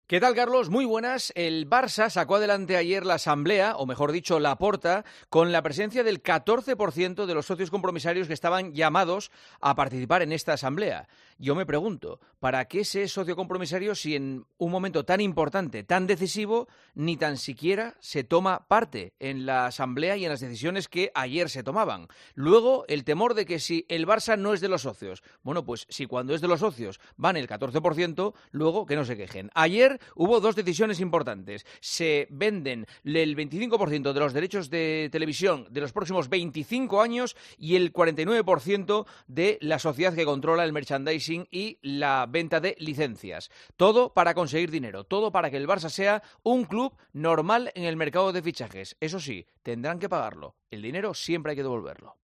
El director de 'El Partidazo de COPE' analiza la actualidad deportiva en 'Herrera en COPE'